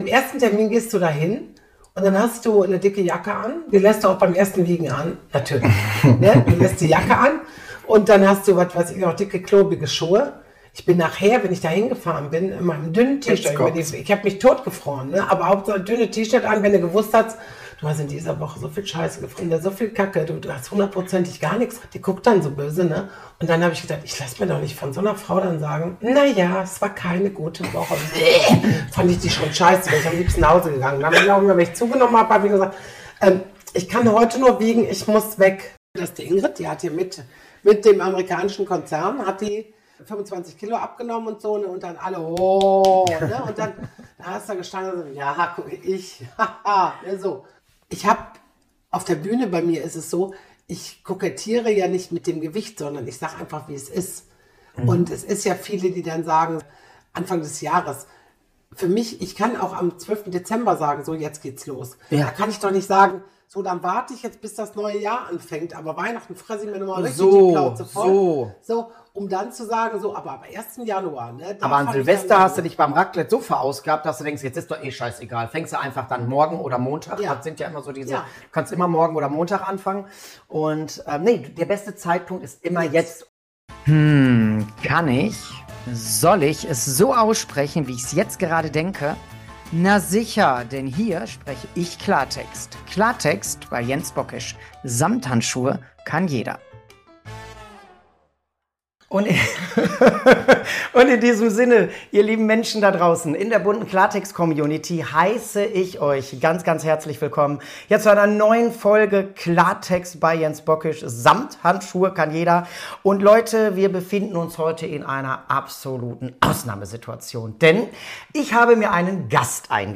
In dieser besonderen Folge begrüße ich Ingrid Kühne, Star-Comedian, Kabarettistin und Humorbeauftragte mit dem Herz auf der Zunge!